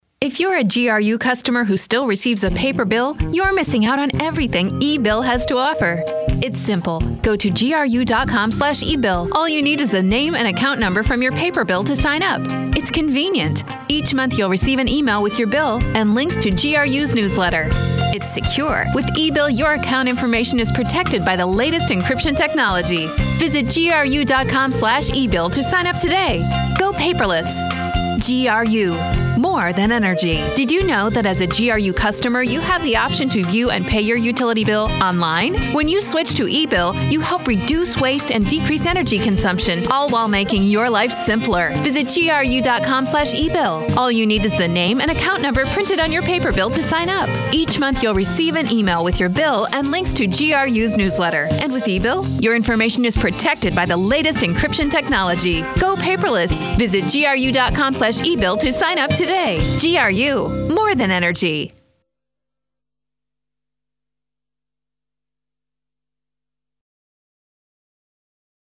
Length Radio Spot